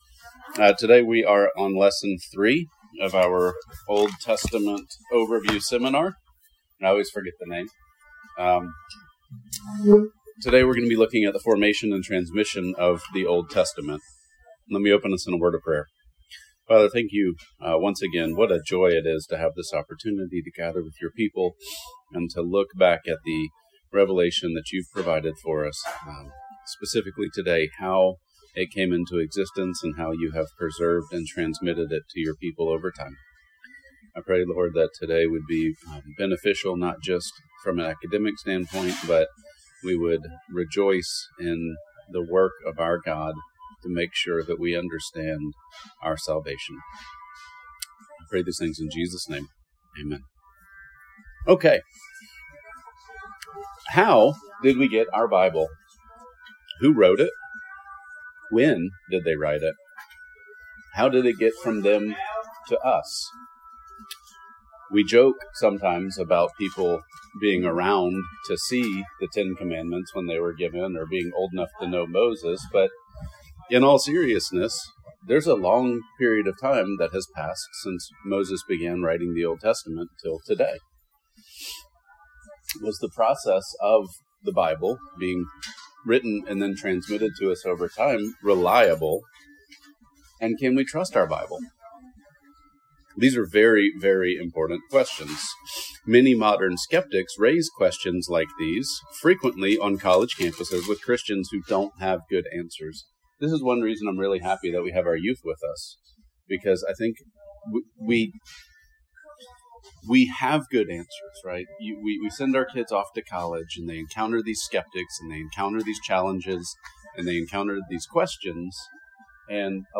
Old Testament Overview Seminar